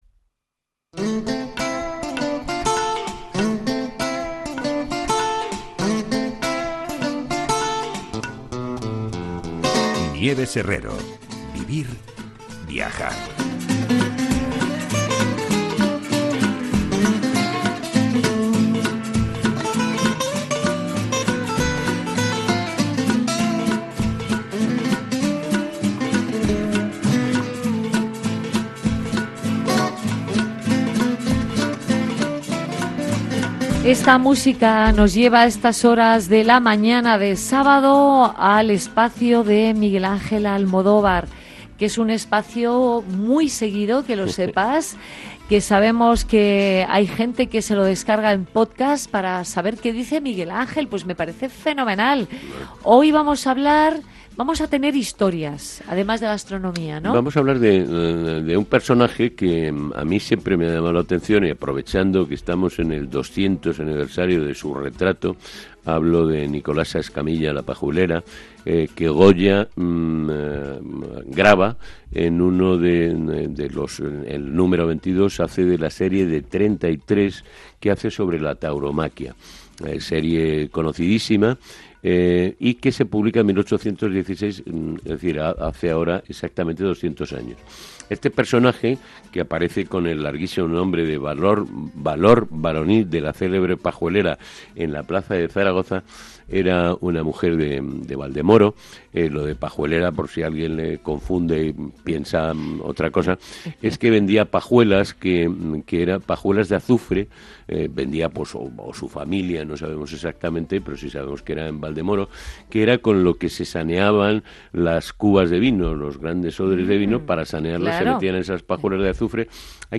Escuche el programa completo en Capitalradio. vivir viajar